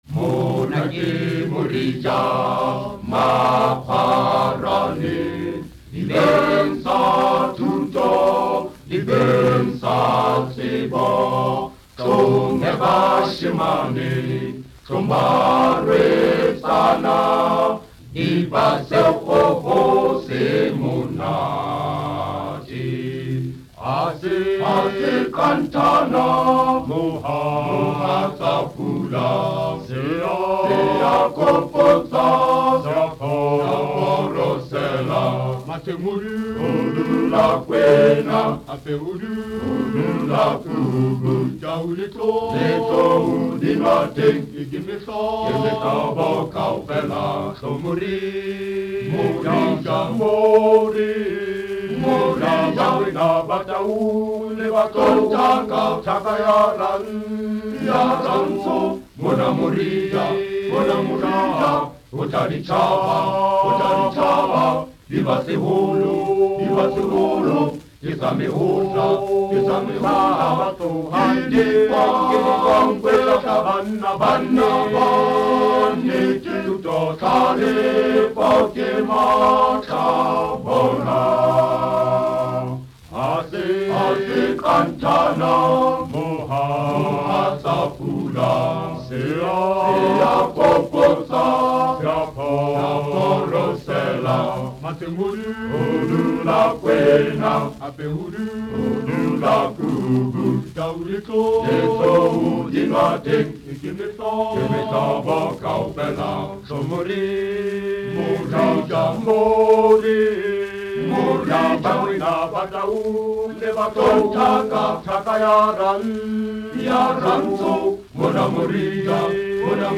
choir SATB
Studio Recording